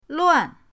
luan4.mp3